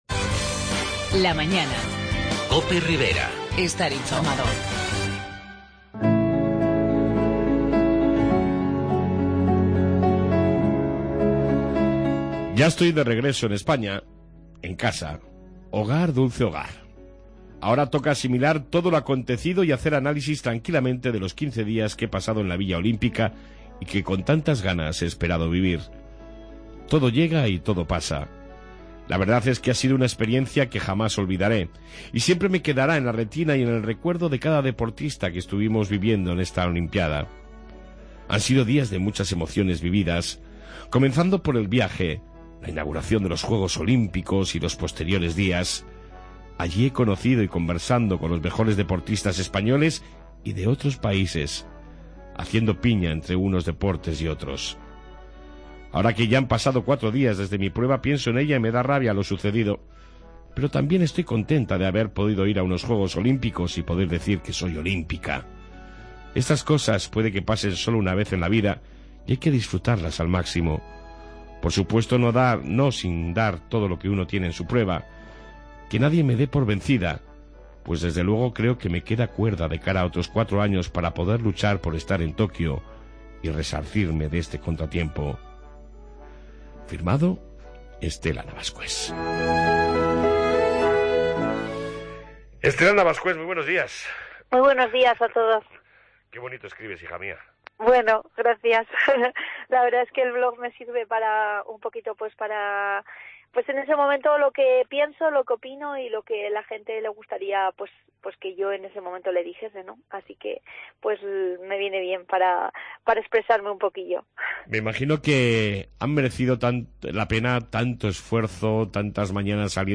Una Tudelana en Rio de Janeiro...Entrevista